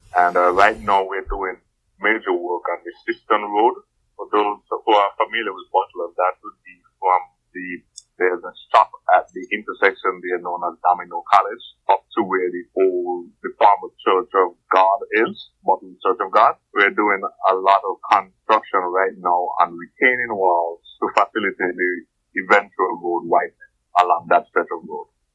Mr. Jevon Williams, Director of the Public Works Department gave us an update about the on-going project: